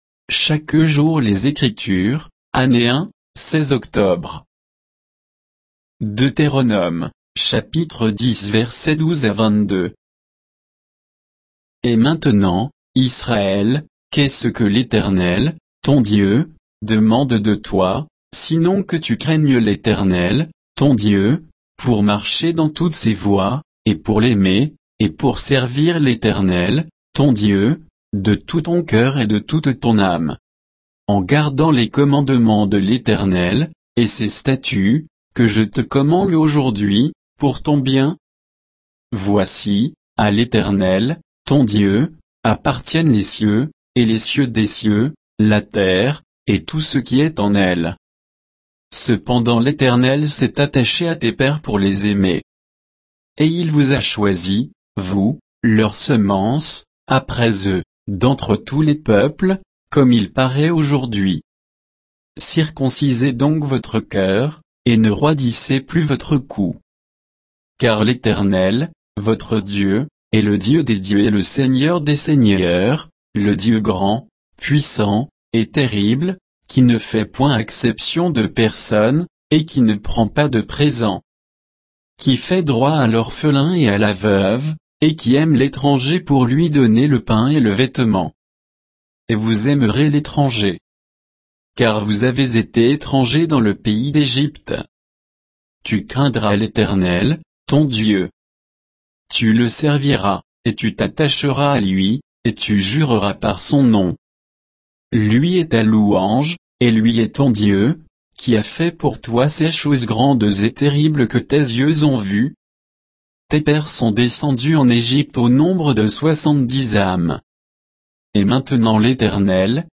Méditation quoditienne de Chaque jour les Écritures sur Deutéronome 10, 12 à 22